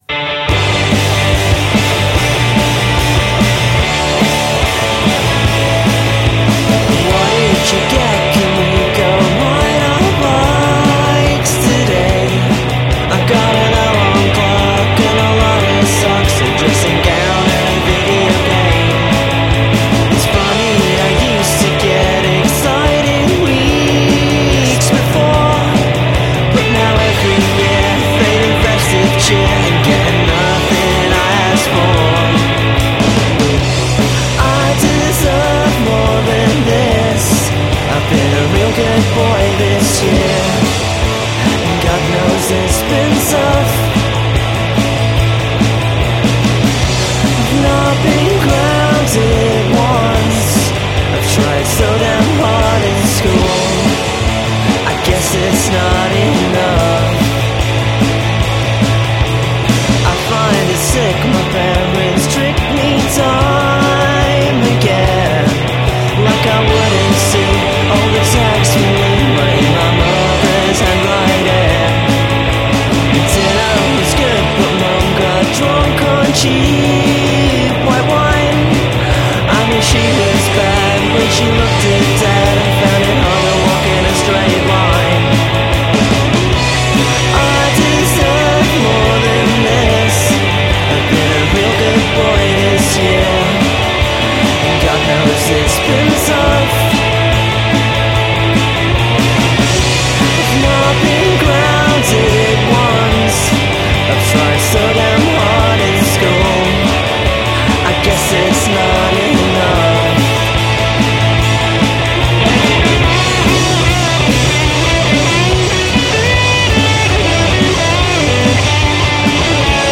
alt-Pop